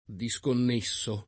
diSkonn$SSo o
diSkonn%SSo] — cfr. nesso